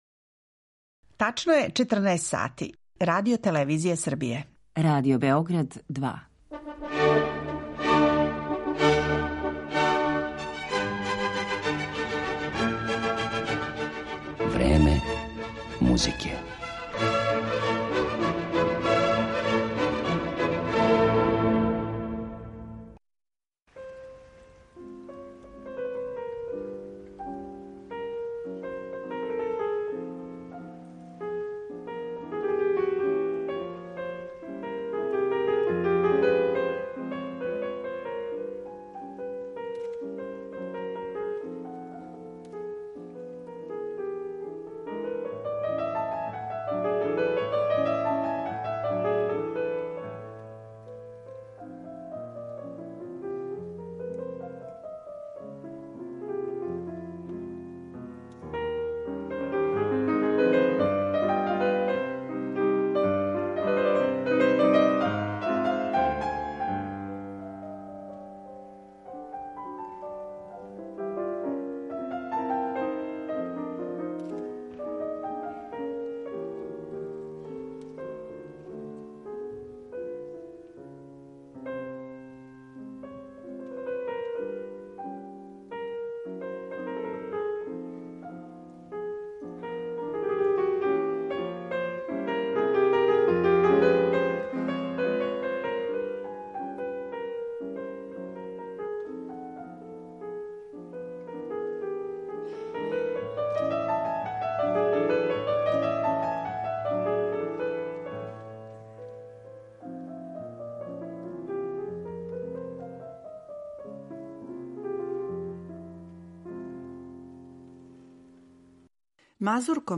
У емисији Време музике слушаћете игре разних словенских народа које су стилизоване и укључене у најразноврснија дела уметничке музике, од инструменталне свите – до опере.